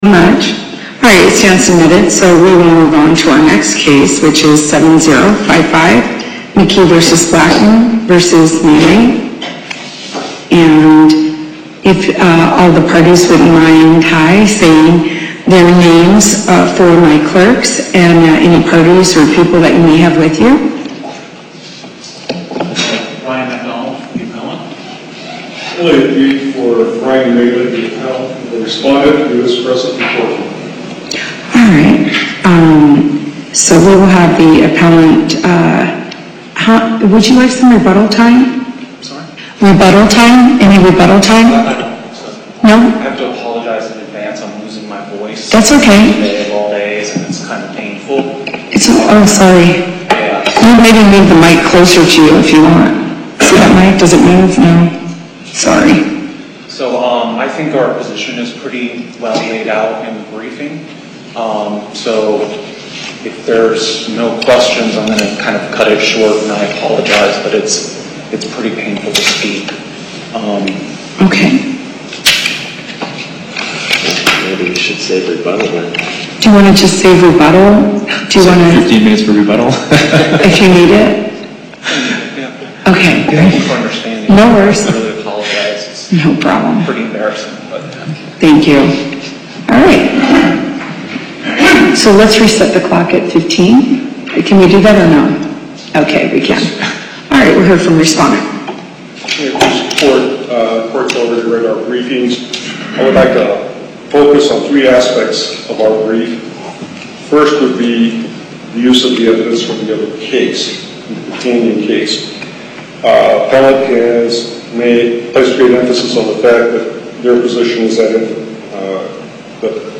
Loading the player Download Recording Docket Number(s): 70555 Date: 01/16/2018 Time: 2:00 P.M. Location: Las Vegas Before the Court of Appeals, Chief Judge Silver presiding.